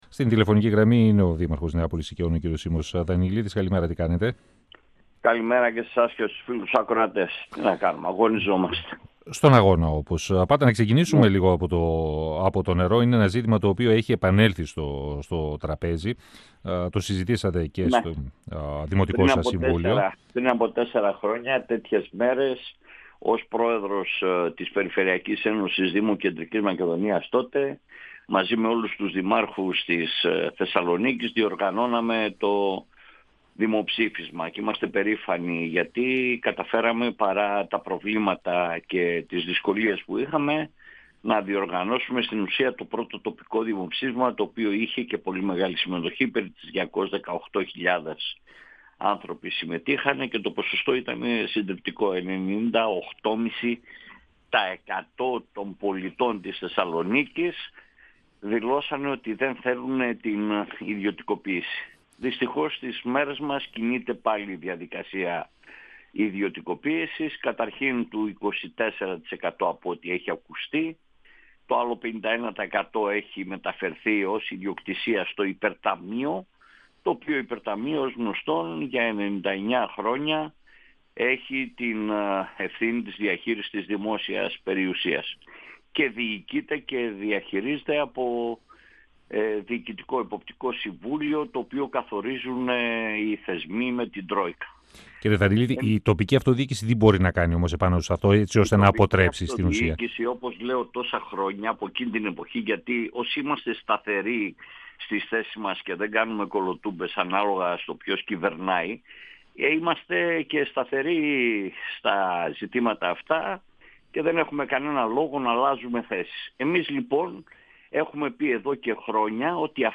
Συνέντευξη
Ο δήμαρχος Νεάπολης Συκεών, Σίμος Δανιηλίδης, στον 102FM του Ρ.Σ.Μ. της ΕΡΤ3 Το νερό αποτελεί κοινωνικό αγαθό και πρέπει να διατηρηθεί ο δημόσιος χαρακτήρας του, επισημαίνουν τοπικοί φορείς και κινήματα πολιτών.